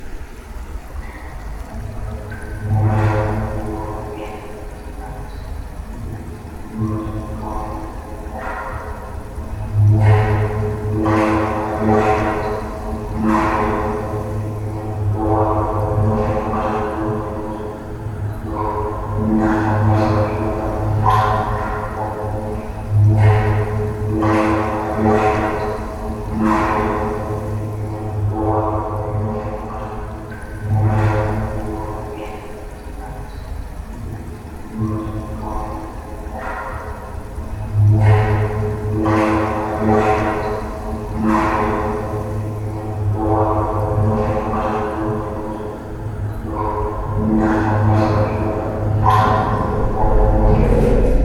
Water Through Metal Pipe
effect howling mystical pipe underground water weird sound effect free sound royalty free Nature